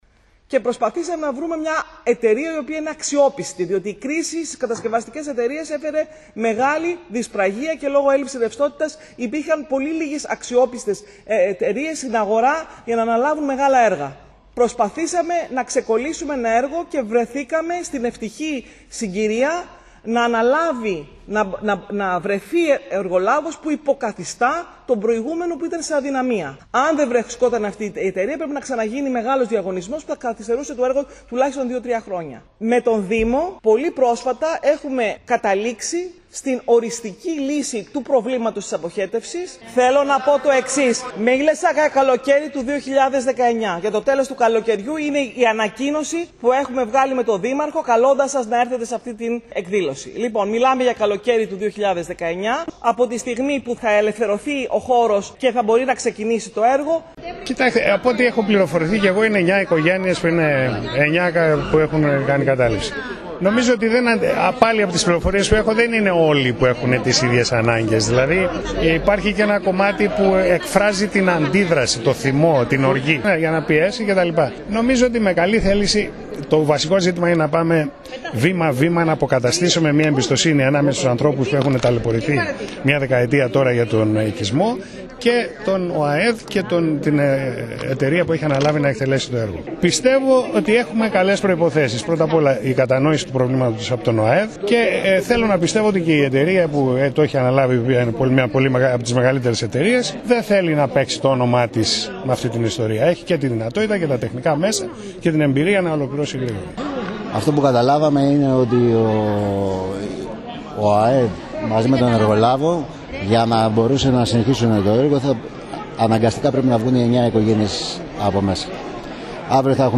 Ακούμε την πρόεδρο του ΟΑΕΔ Μαρία Καραμεσίνη, το Δήμαρχο Κώστα Νικολούζο